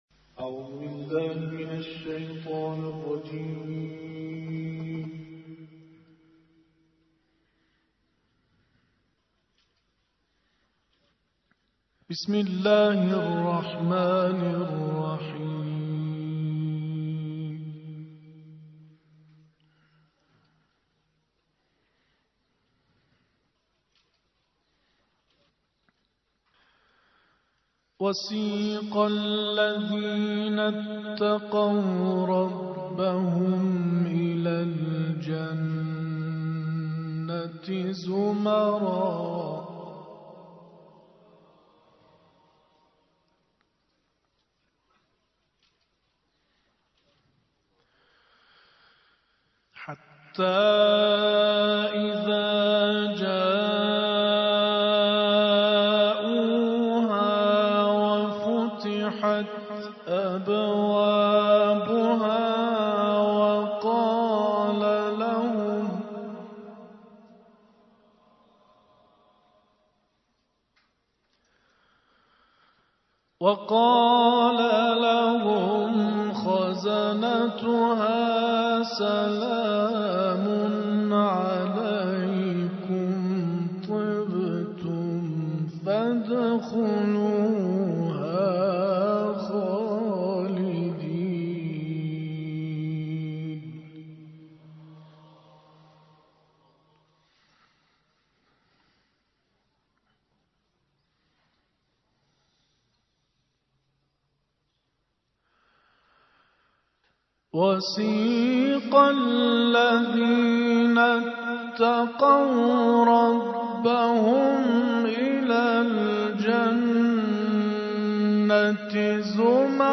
تلاوت استودیویی سوره مبارکه انفطار